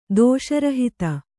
♪ dōṣa rahita